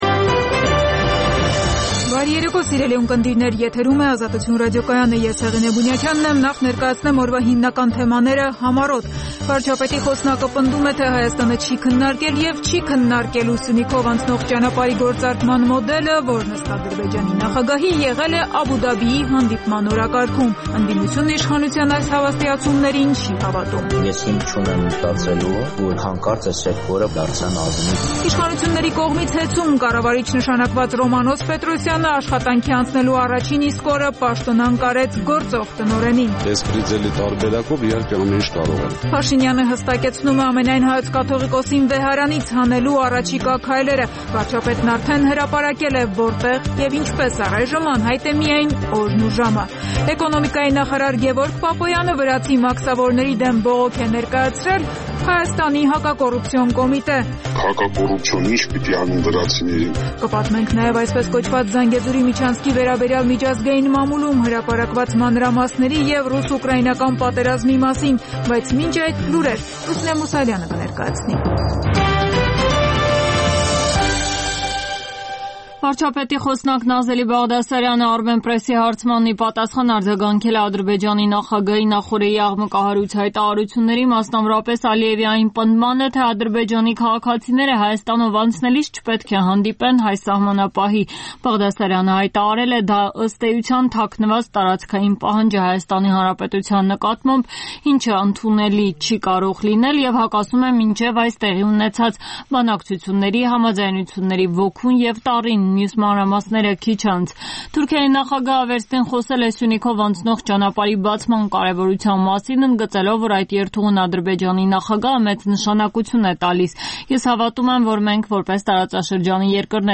«Ազատություն» ռադիոկայանի օրվա հիմնական թողարկումը: Տեղական եւ միջազգային լուրեր, ռեպորտաժներ օրվա կարեւորագույն իրադարձությունների մասին, հարցազրույցներ, մամուլի տեսություն: